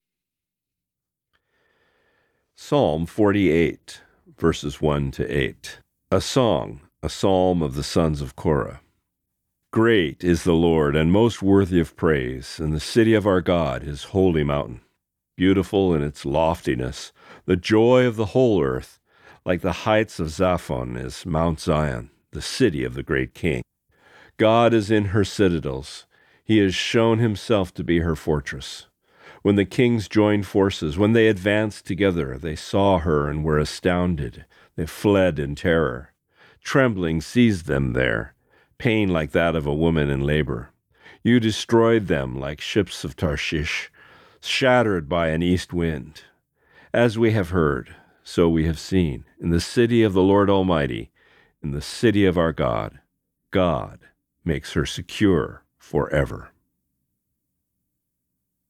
Reading: Psalm 48:1-8